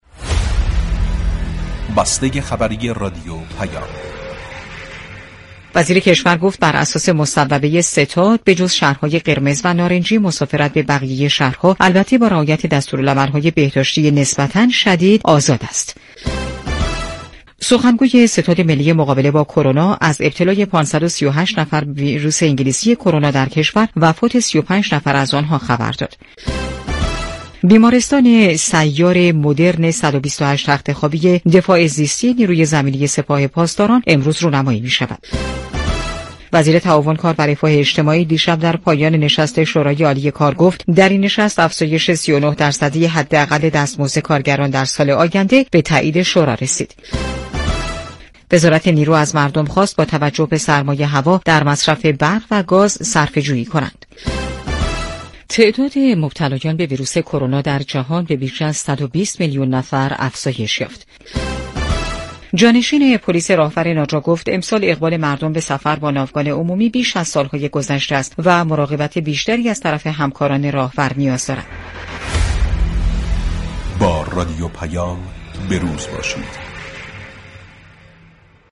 بسته خبری رادیو پیام
گزیده اخبار پخش شده در رادیو پیام را در بسته ی خبری رادیو پیام بشنوید .